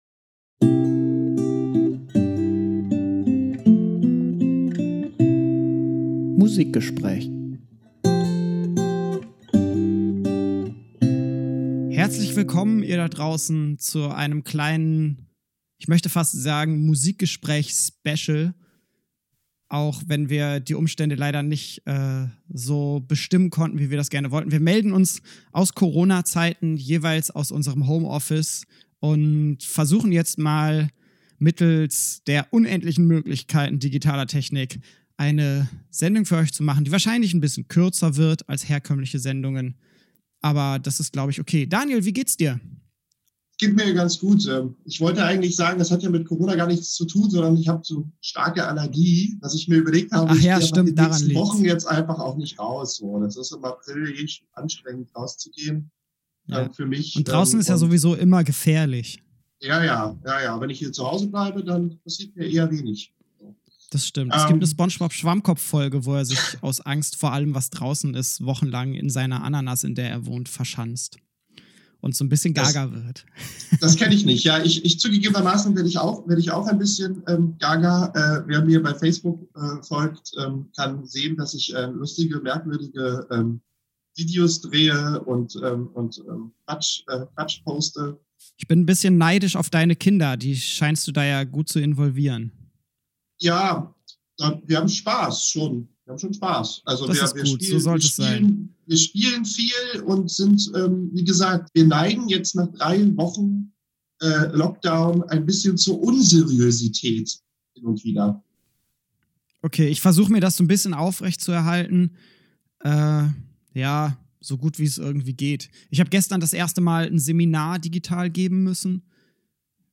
Leider hat die Technik uns etwas Probleme bereitet, Flow und Tonqualität haben darunter etwas gelitten.
Dennoch viel Spaß mit dem Musikgespräch aus dem Home Office!